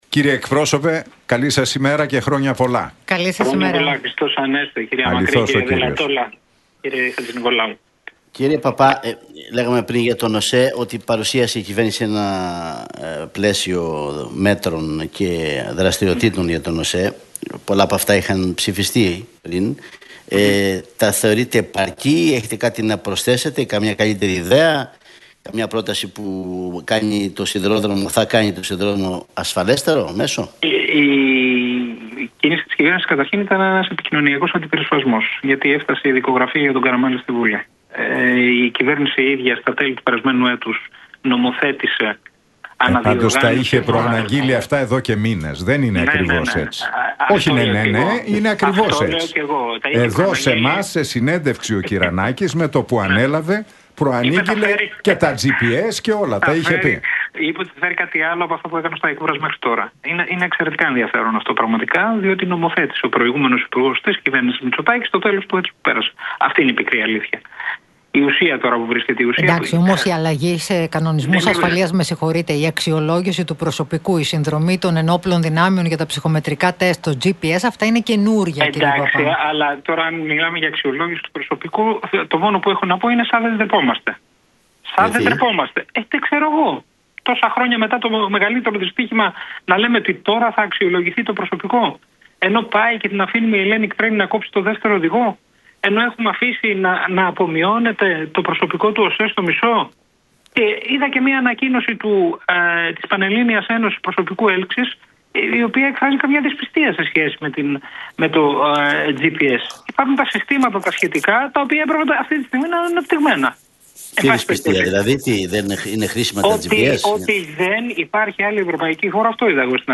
Παππάς στον Realfm 97,8: Το σχέδιο Κωνσταντοπούλου συναντιέται με το σχέδιο Μητσοτάκη απέναντι στην Αριστερά — ΔΕΔΟΜΕΝΟ